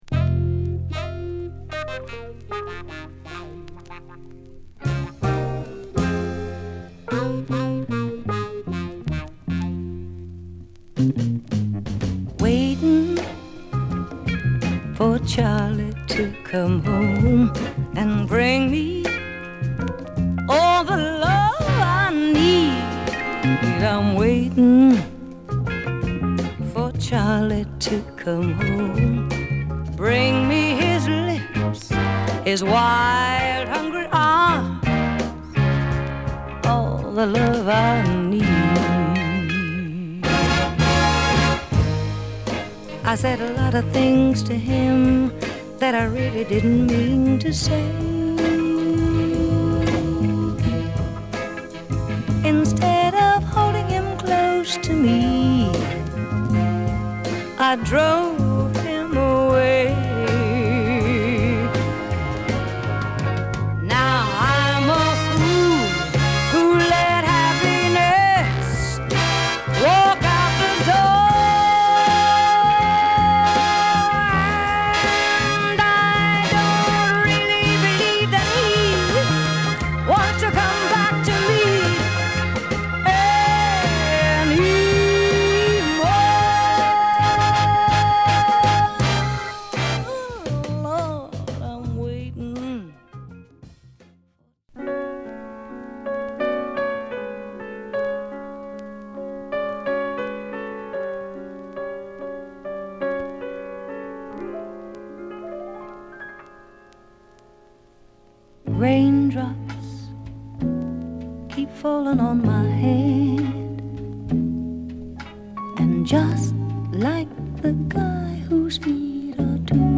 US